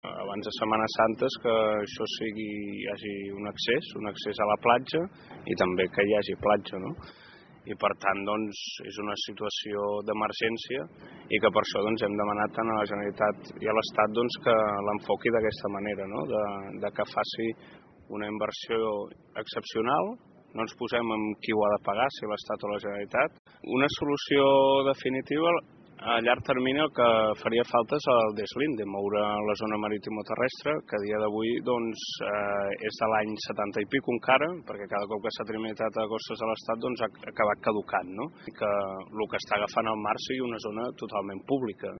Els alcaldes han demanat una actuació d’urgència per poder recuperar la zona amb la vista posada en la primavera, quan es reprèn l’activitat turística. Són declaracions de Joan Mercader, alcalde de Malgrat de Mar.